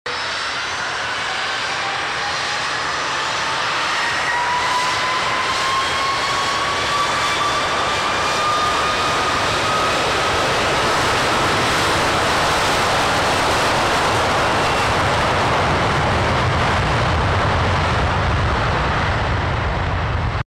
Screaming A330 Departure! 🔊 Sound Effects Free Download